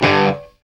GTR STONE0CL.wav